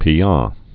(pē)